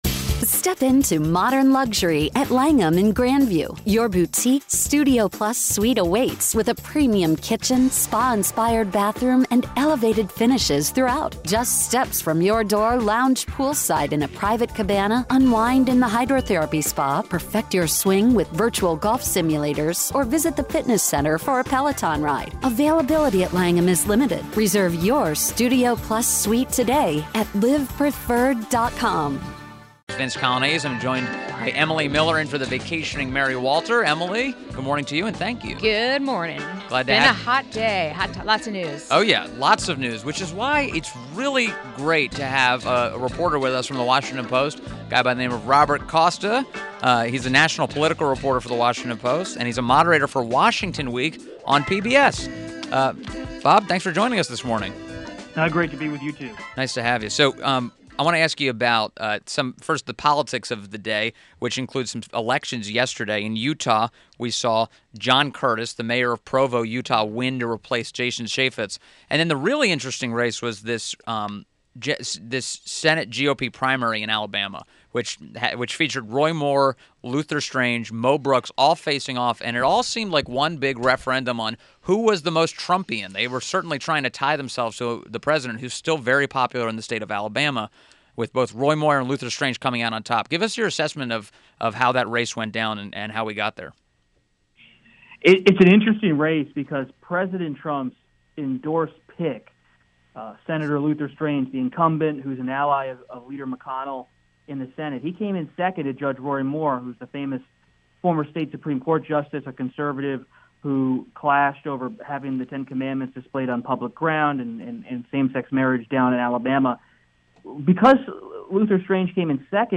WMAL Interview - ROBERT COSTA - 08.16.17
INTERVIEW – ROBERT "BOB" COSTA – National political reporter, Washington Post and Moderator for Washington Week on PBS